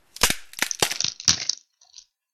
Breaking_Stick.ogg